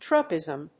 /tróʊpɪzm(米国英語), trˈəʊpɪzm(英国英語)/